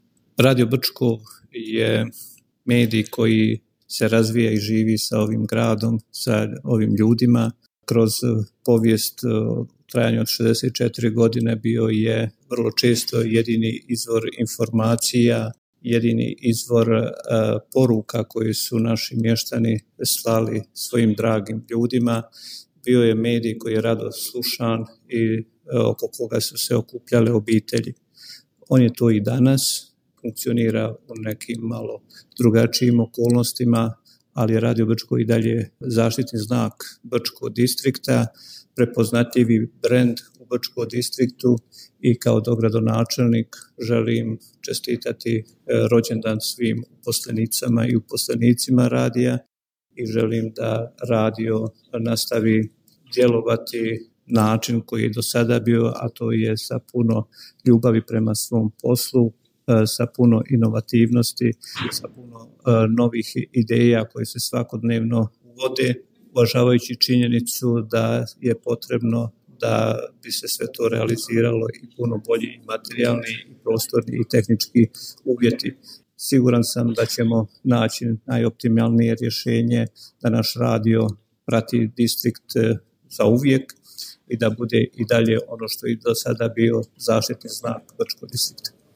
Čestitka dogradonačelnika dr. Ante Domića u povodu 64. godišnjice Radija Brčko
Dogradonačelnik Brčko distrikta BiH dr. Anto Domić uputio je čestitku u povodu 64. godišnjice Radija Brčko koju možete poslušati u nastavku: